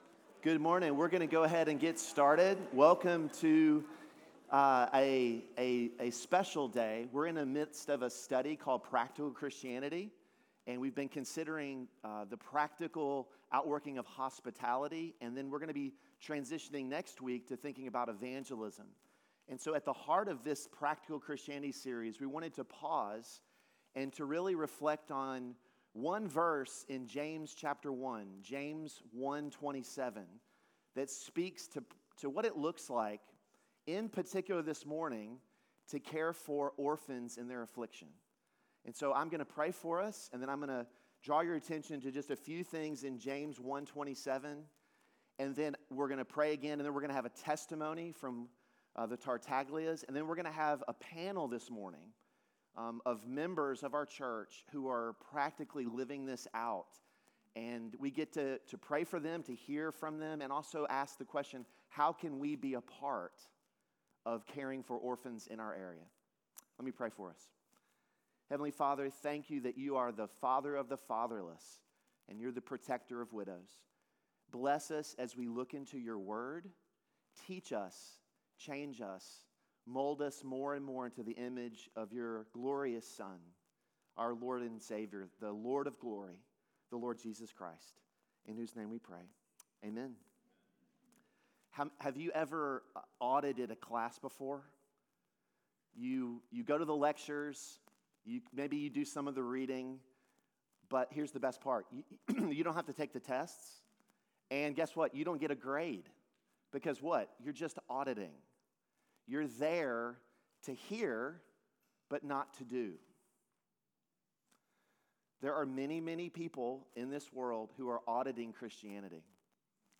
Sermon Adult Bible Fellowship | University Baptist Church